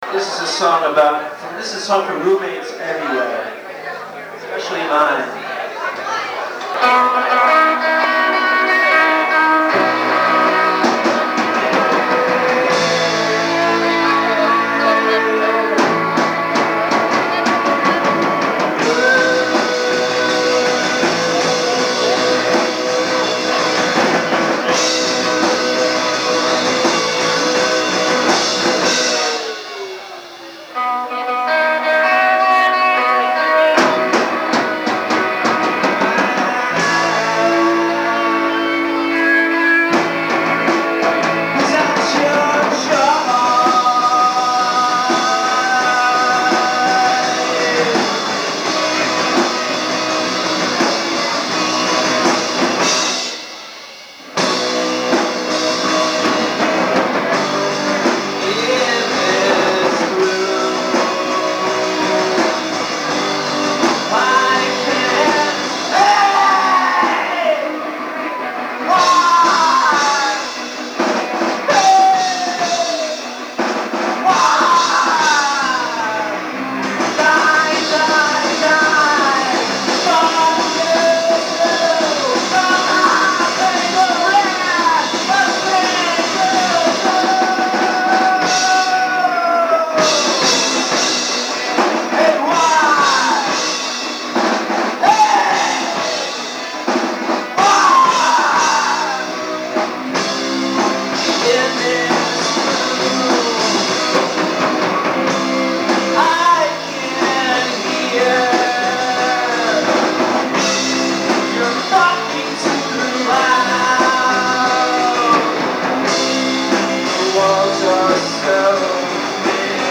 guitar
Bass
Drums
Live